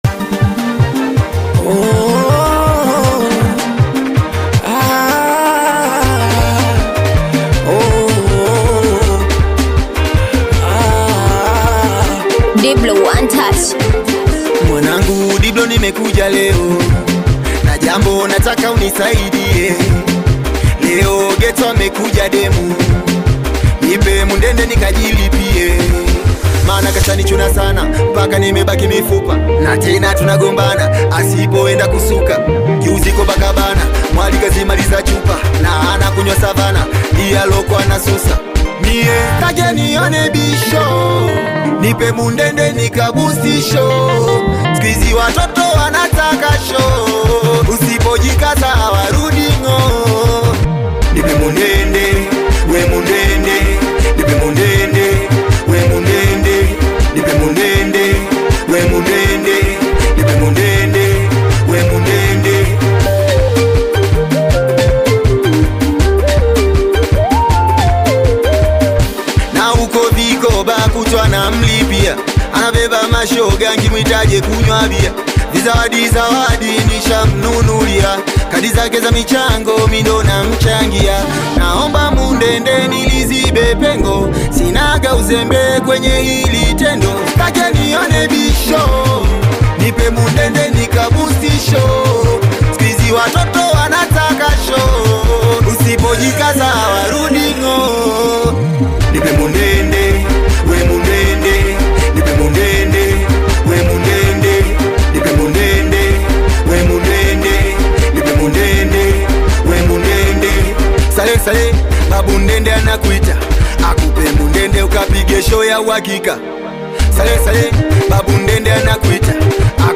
bongo flava
Singeli song
African Music